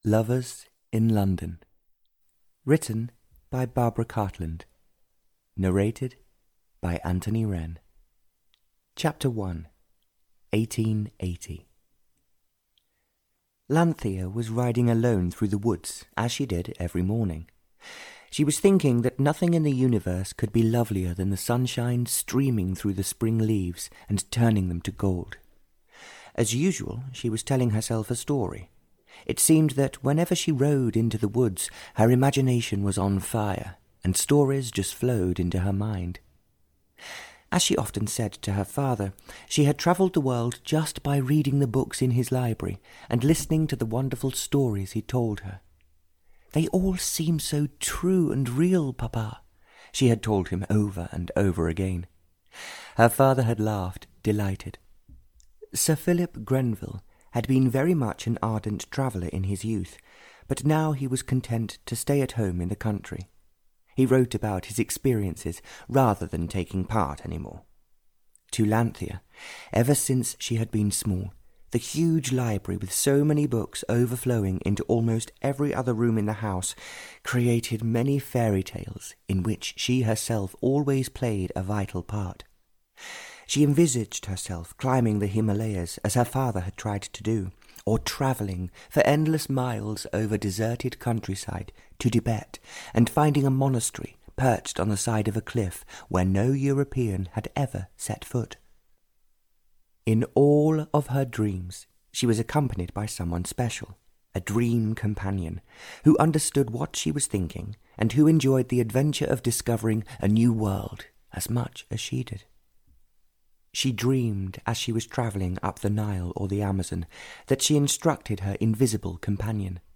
Ukázka z knihy